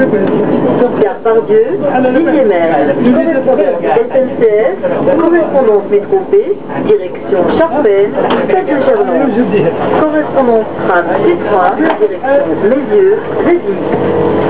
Annonce tram T1